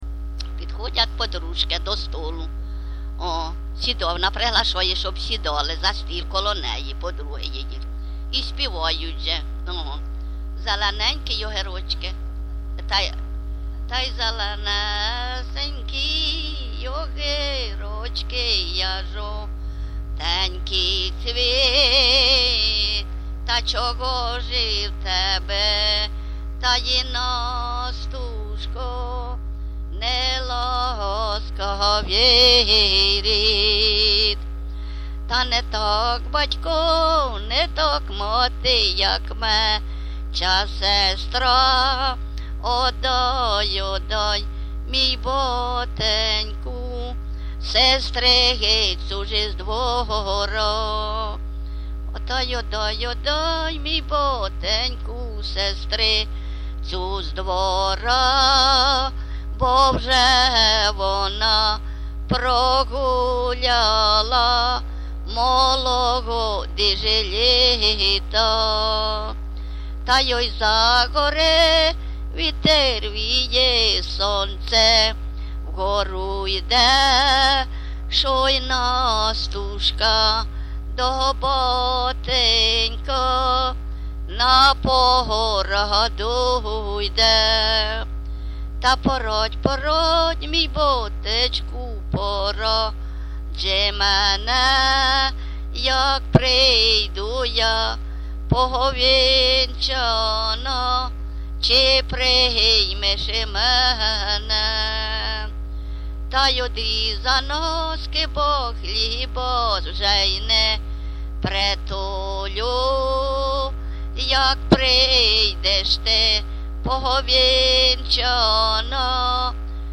ЖанрВесільні
Місце записус. Нижні Рівні, Чутівський район, Полтавська обл., Україна, Слобожанщина